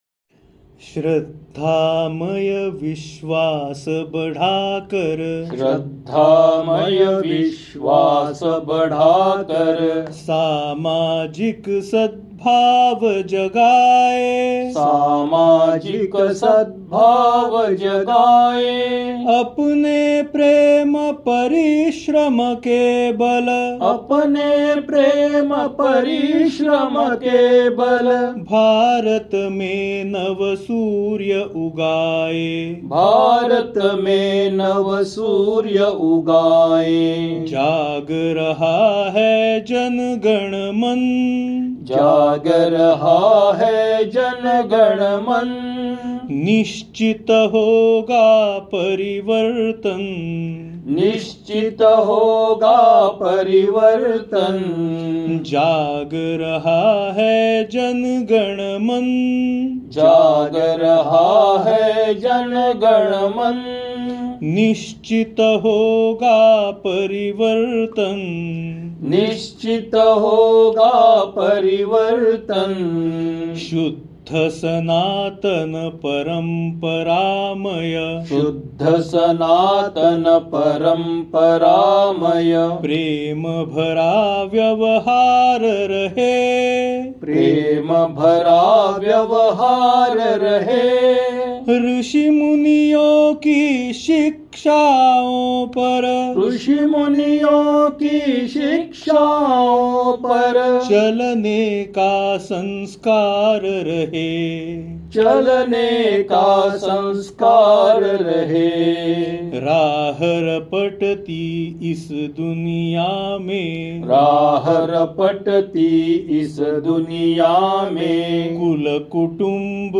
Patriotic Songs Collections